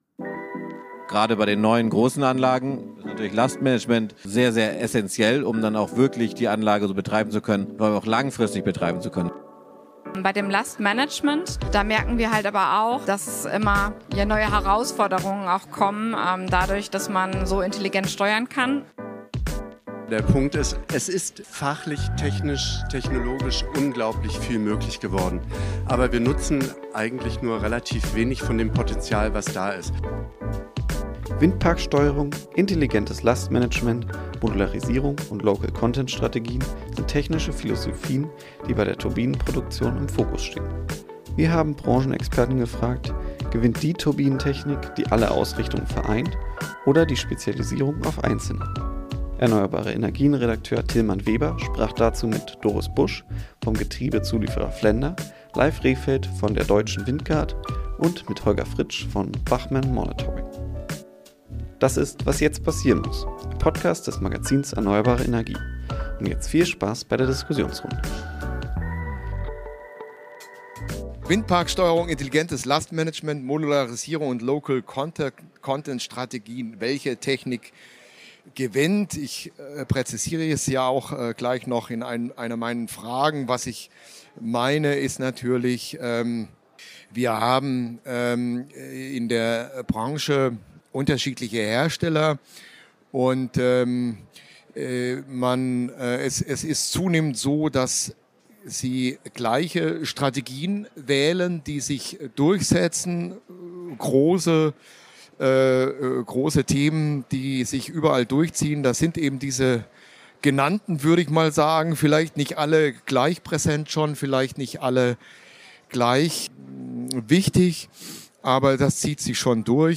Im Rahmen einer Diskurs-Trilogie stellten wir am dritten Messetag in der letzten von drei Talkrunden am Messestand von ERNEUERBARE ENERGIEN die Windparksteuerung, intelligentes Lastmanagement, die Modularisierung und Local-Content-Strategien als technische Philosophien der Turbinenproduktion in den Mittelpunkt. Wir fragen drei branchenbekannte Experten, welche Turbinentechnik gewinnt: Diejenige die alle diese vier Grundausrichtungen oder möglichst viele davon vereint?